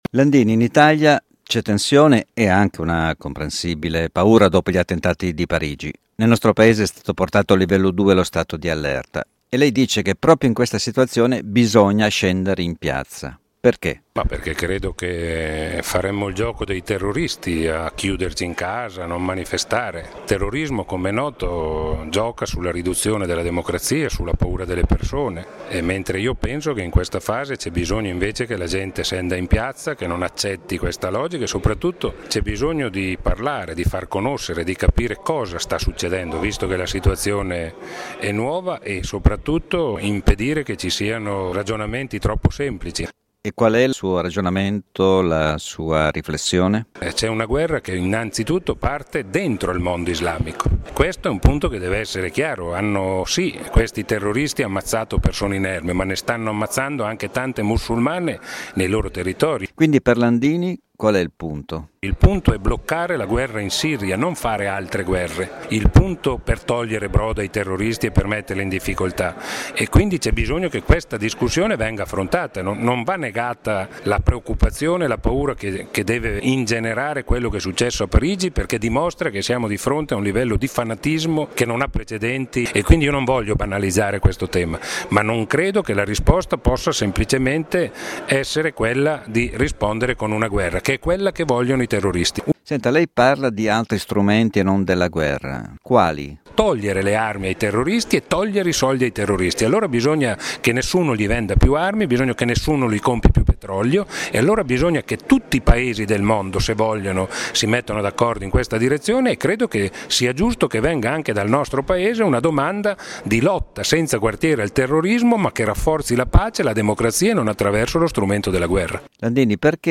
Ascolta l’intervista integrale al leader della Fiom Maurizio Landini
Intervista a Maurizio Landini